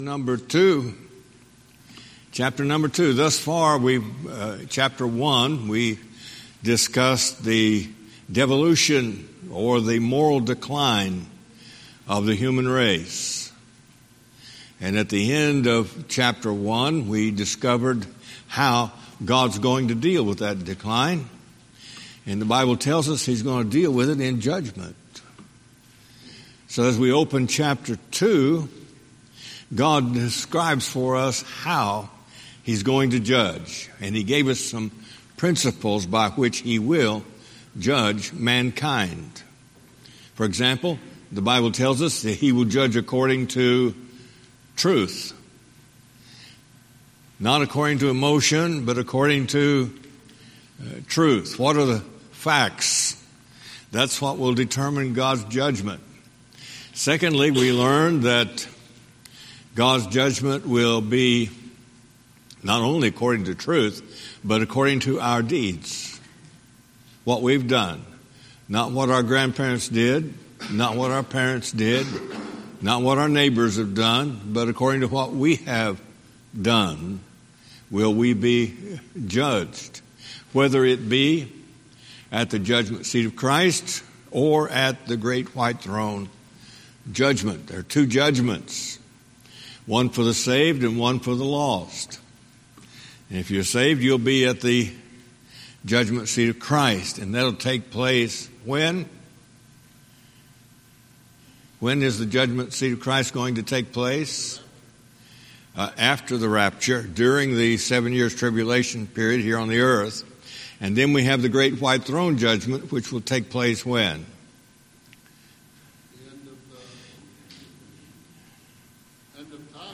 Sunday School Recordings